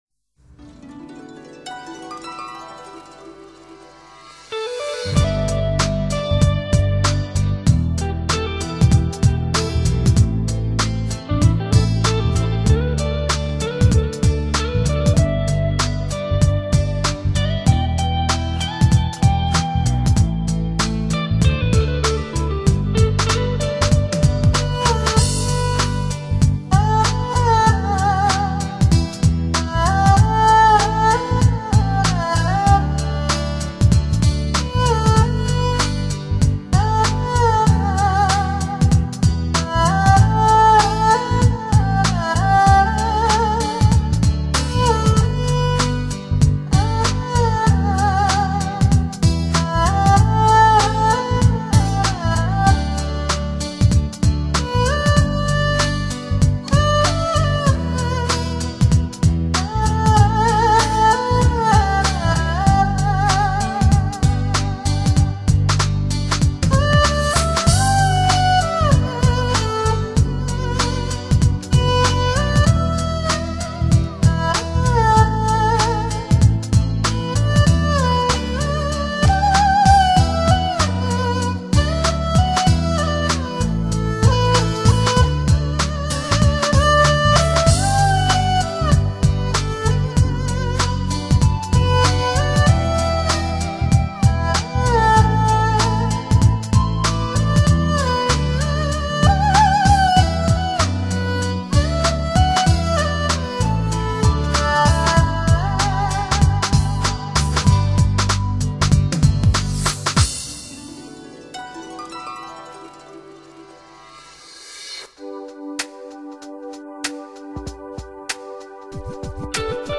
别样的二胡乐声中，回忆是山温水暖，离别是哀而不伤，牵挂是风情缱绻，爱情是浅浅清清。
二胡沿着时间的年轮轻吟浅唱。